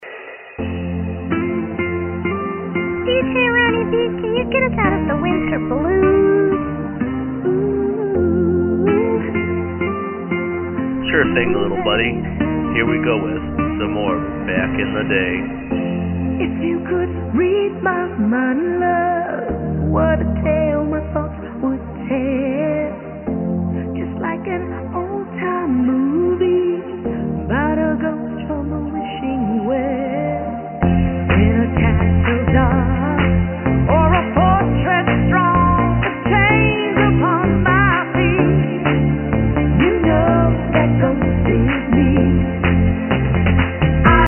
~All tracks are Remixes, unless otherwise stated~